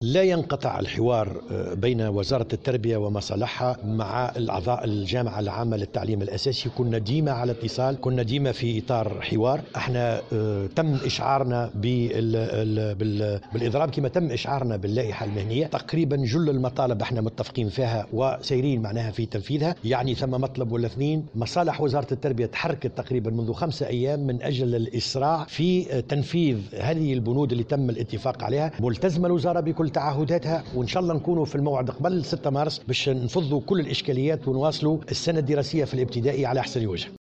وأضاف في تصريح لمراسلة الجوهرة اف ام ليوم الخميس 14 فيفري 2019، أنه تم الاتفاق حول جل المطالب باستثناء نقطتين فقط، مؤكدا أن الوزارة ملتزمة بتعهداتها وتعمل على تنفيذ البنود وفض الإشكاليات قبل موعد الإضراب.